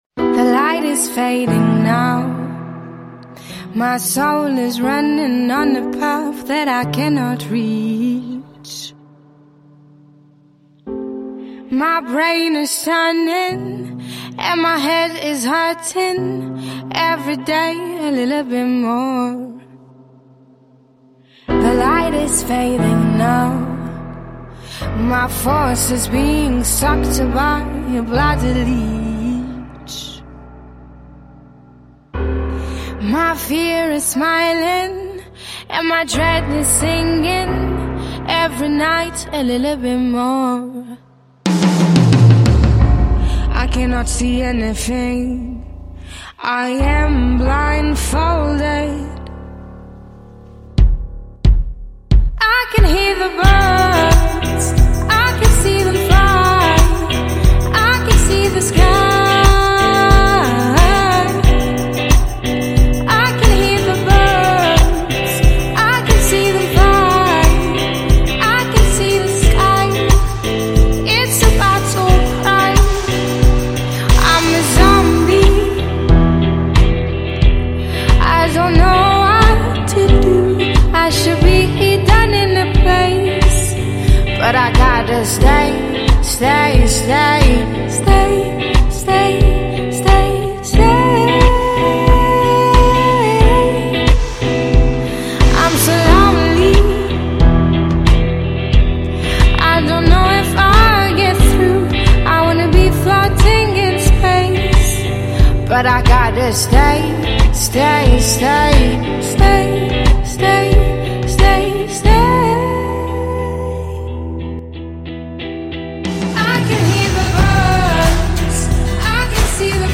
MP3 / Korg / Paroles / ....Video